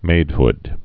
(mādhd)